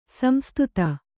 ♪ samstuta